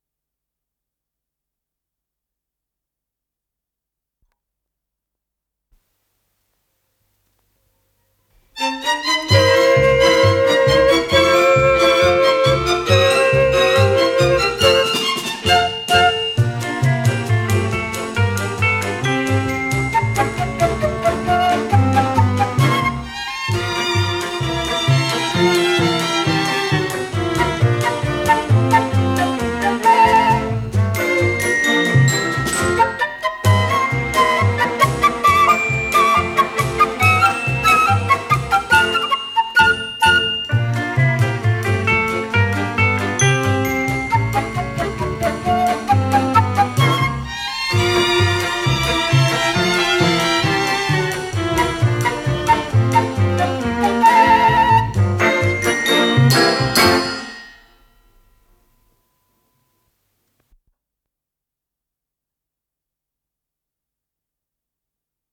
с профессиональной магнитной ленты
ПодзаголовокЗаставка, фа мажор
ВариантДубль моно